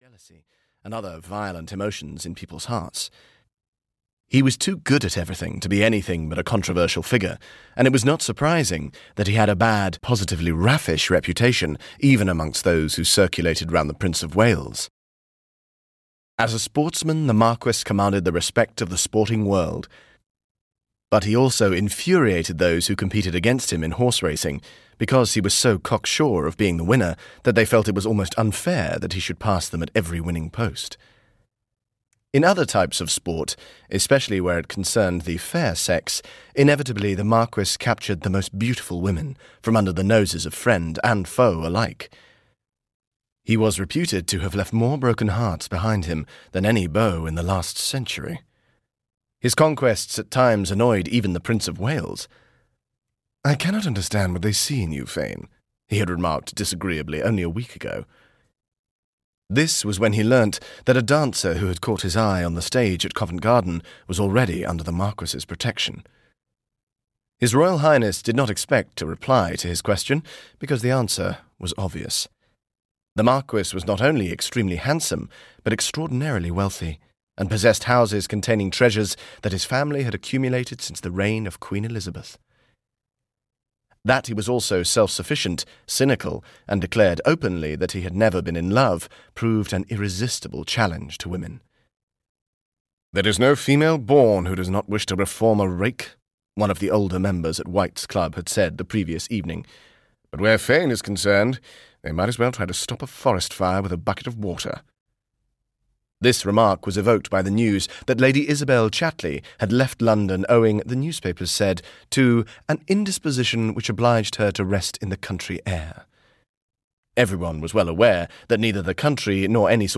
Who Can Deny Love? (EN) audiokniha
Ukázka z knihy